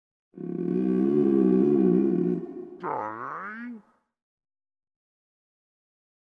Звуки урчания живота
В подборке представлены реалистичные аудиофайлы: от громкого бурления голодного желудка до тихого бульканья после еды.
Громкий рычащий звук